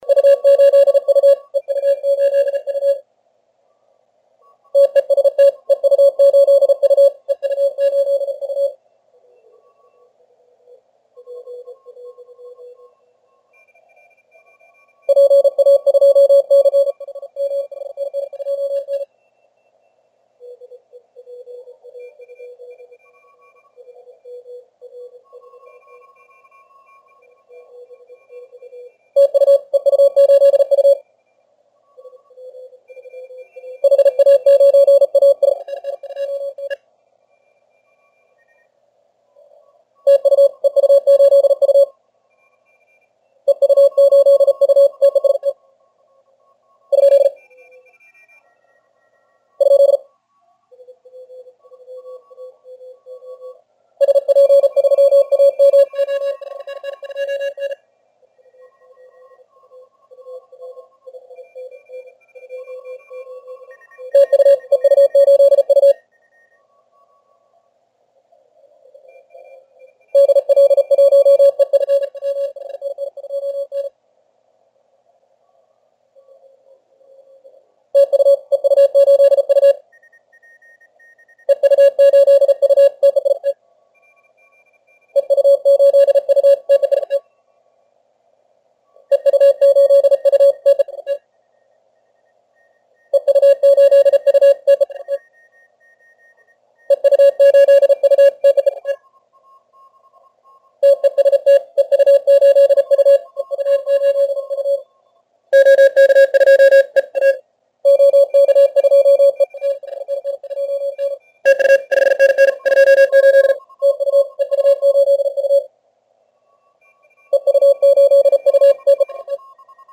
Сегодня решил проверить динамический диапазон приемника в экстримальных условиях теста.
:) Слышны помехи от соседей с большой мощностью. Первые файлы с телеграфного участка.
Запись велась: в CW 200Hz, в SSB 2,4kHz.